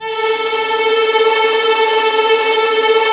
Index of /90_sSampleCDs/Roland LCDP08 Symphony Orchestra/STR_Vls Tremolo/STR_Vls Trem wh%